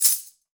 Percs
Maaly Raw Shaker 1.wav